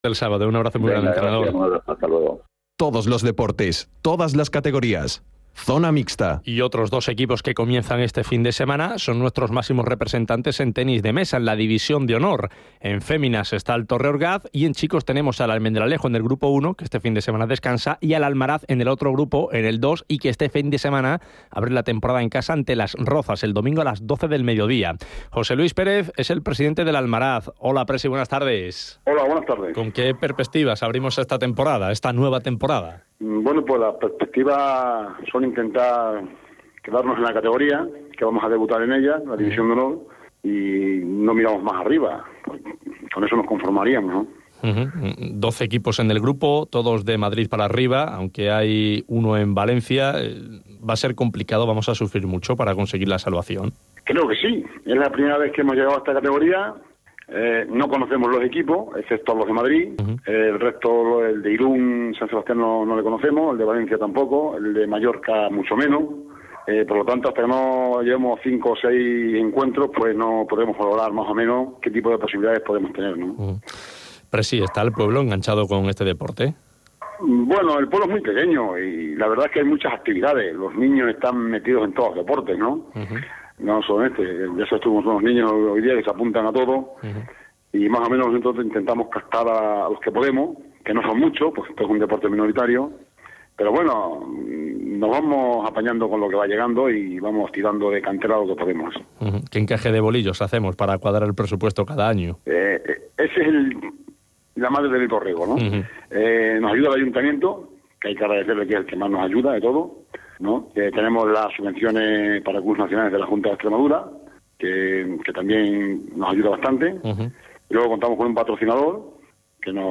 radio_extremadura_3-10-13.mp3